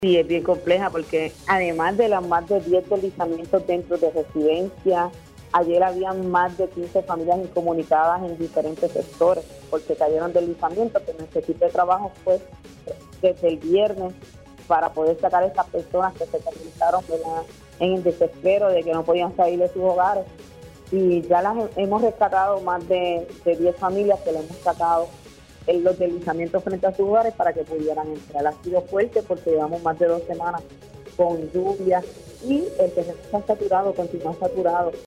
La alcaldesa de Aguas Buenas, Karina Nieves indicó en Pega’os en la Mañana que 26 familias de la comunidad Lupe Camacho están incomunicadas luego de que las intensas lluvias este fin de semana agravaron un socavón en una carretera del área.